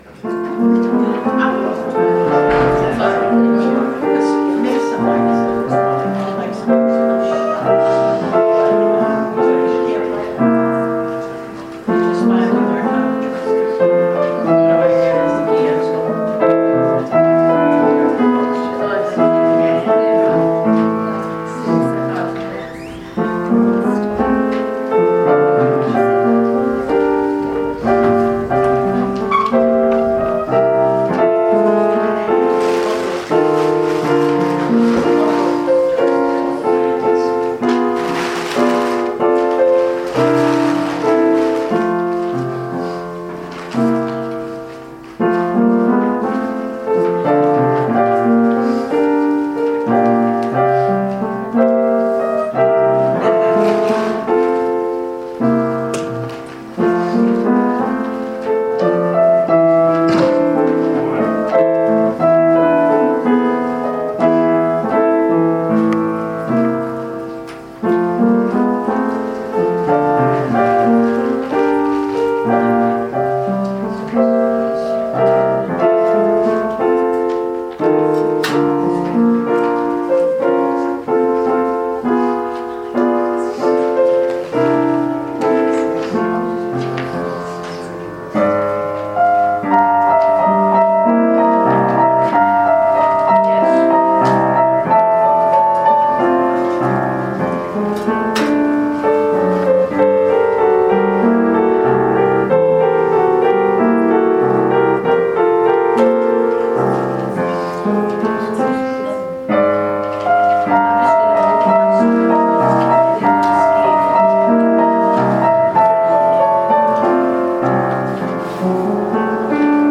Worship Service April 28, 2019 | First Baptist Church, Malden, Massachusetts
Sermon
Offertory Anthem
Pianist
Praise and Worship Singing